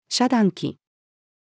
遮断器-female.mp3